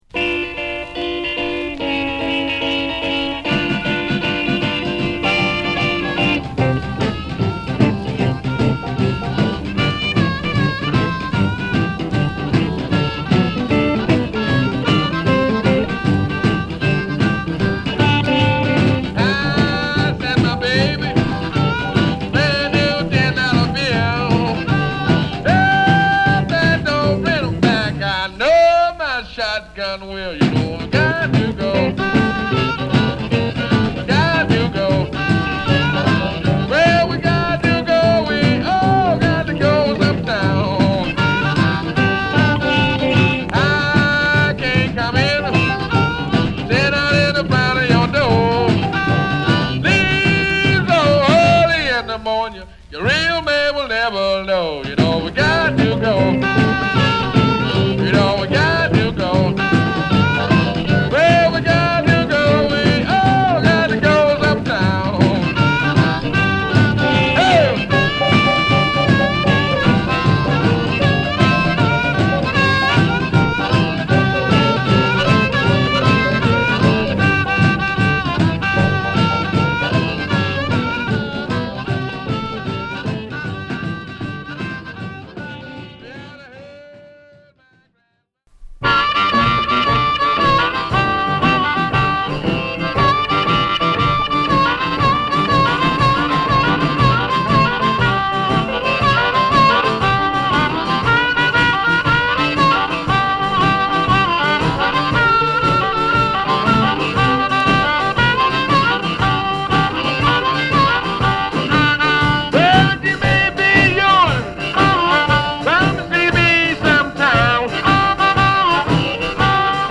ドラム／ギター／ハープに味わい深いヴォーカルもこなすワンマン・バンドスタイルで
ゴキゲンブルースを満載！